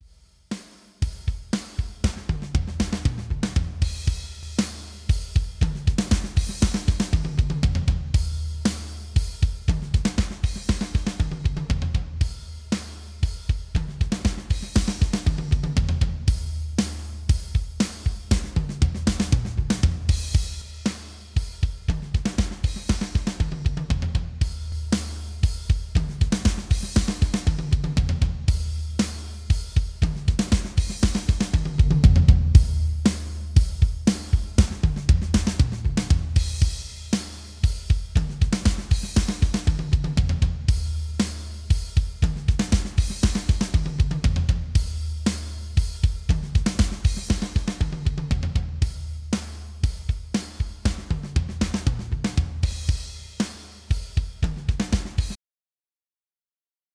Вот еще тест - попеременно (байпас - SSL FUSION ) , в конце байпас. слушайте характер верха.